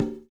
12 CONGA.wav